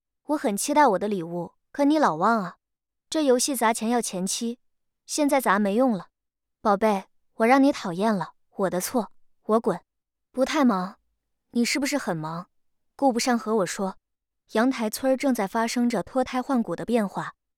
数据堂TTS录音棚通过清华大学建筑环境检测中心检测，达到专业级NR15声学标准，混响时间小于0.1秒，背景噪音小于20dB(A)。
中文普通话，清冷女声